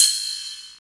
Index of /90_sSampleCDs/USB Soundscan vol.01 - Hard & Loud Techno [AKAI] 1CD/Partition D/16-SOFT KIT
HH 9      -R.wav